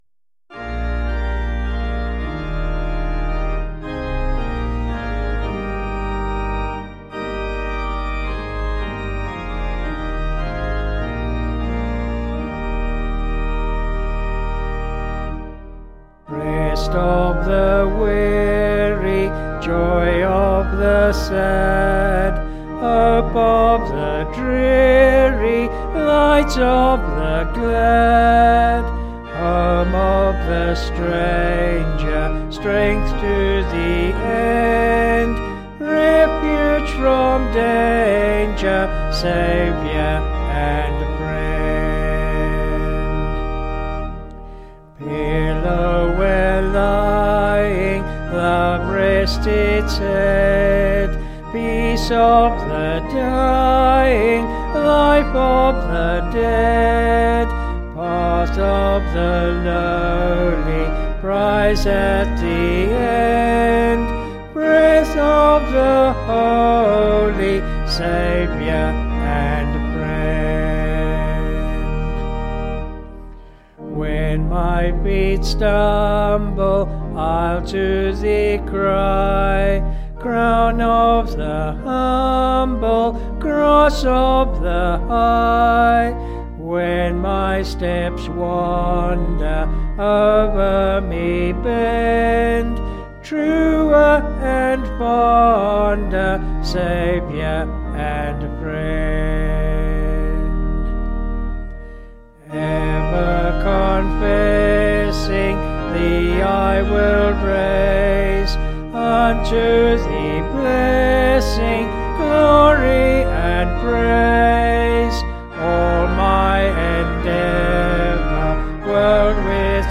(BH)   4/Eb
Vocals and Organ   263.6kb Sung Lyrics